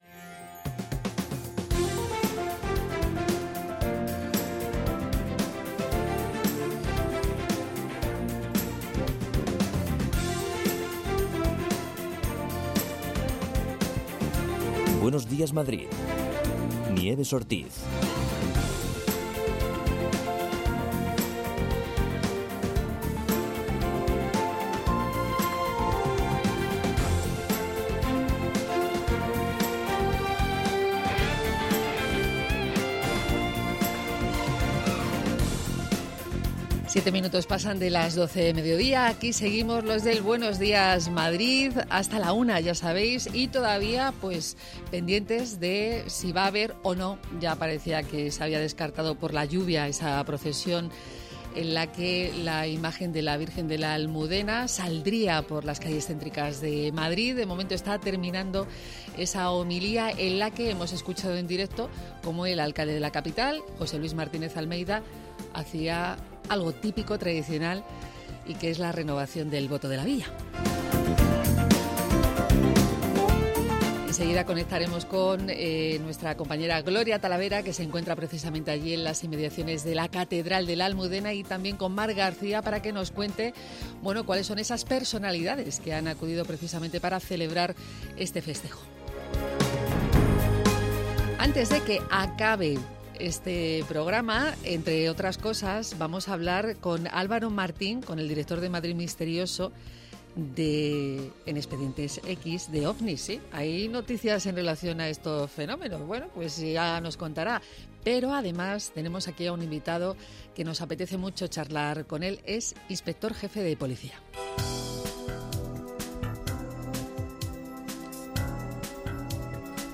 Magacín matinal que busca informar desde las 6 de la mañana a los madrileños analizando lo que ocurre en la actualidad con conexiones en directo desde los puntos calientes de la actualidad, reportajes y entrevistas.&nbsp